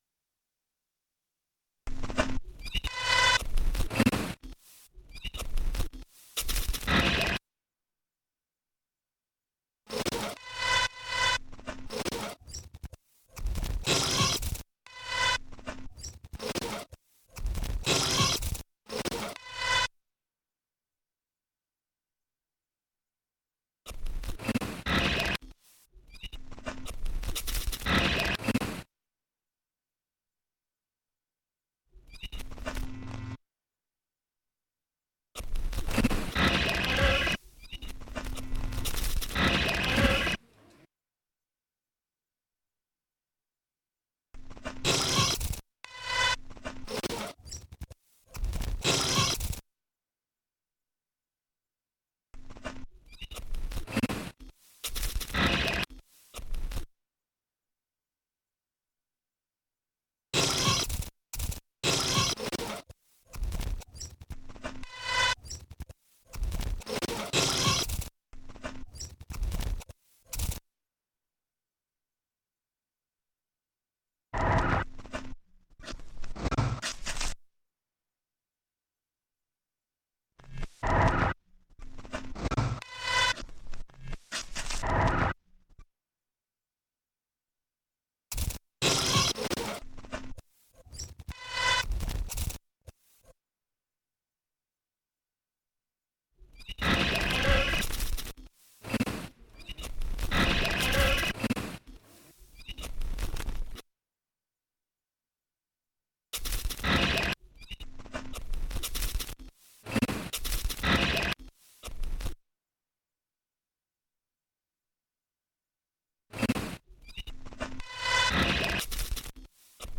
Digitalisiert von MiniDisk.